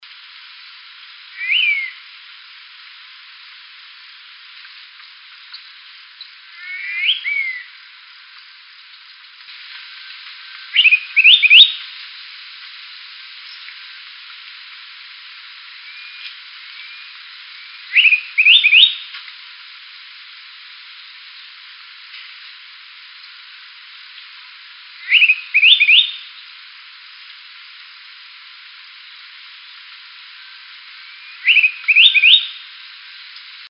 Soldadinho (Antilophiagaleata)
Durante o período de reprodução, o macho canta e defende seu território com energia, mas é a fêmea quem constrói o ninho e cuida dos filhotes.
Seu canto é alegre e pode ser reconhecido por quem caminha atento pela mata